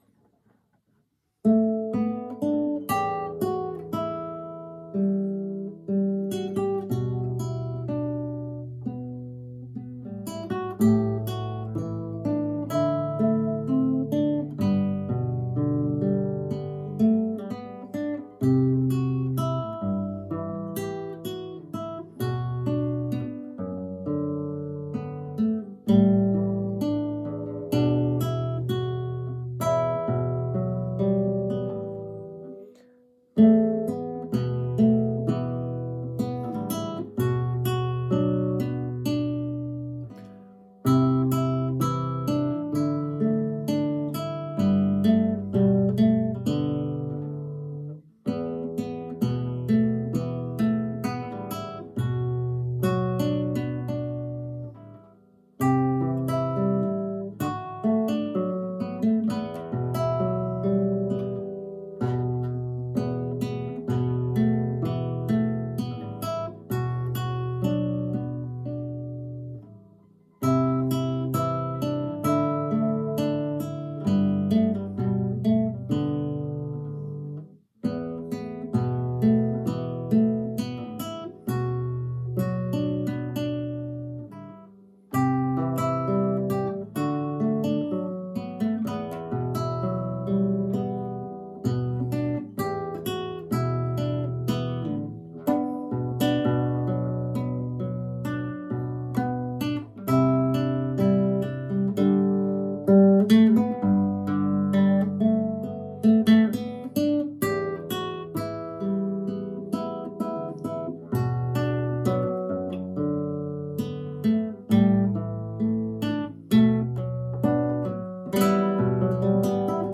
My solo guitar arrangements